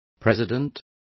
Complete with pronunciation of the translation of president.